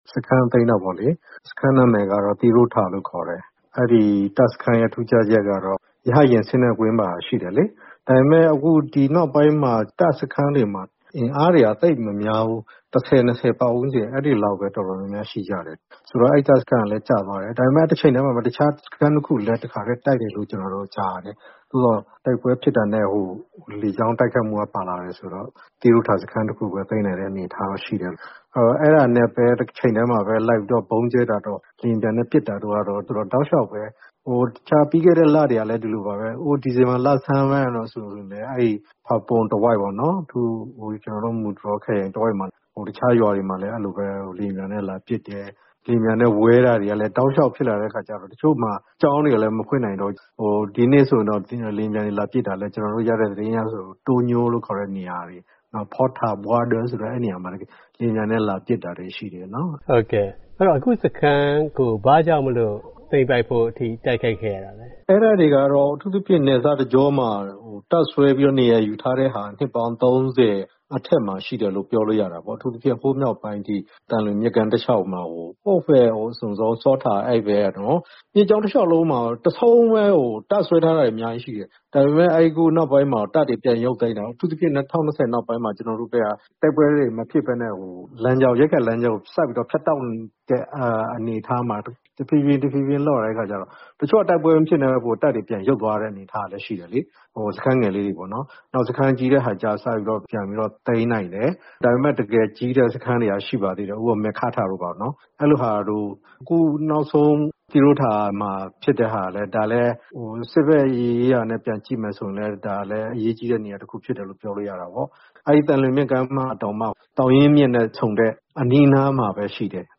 ကရင်ပြည်နယ် စစ်ရေးတင်းမာမှုအပေါ် KNU နဲ့ ဆက်သွယ်မေးမြန်းချက်